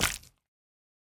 Minecraft Version Minecraft Version snapshot Latest Release | Latest Snapshot snapshot / assets / minecraft / sounds / block / honeyblock / break1.ogg Compare With Compare With Latest Release | Latest Snapshot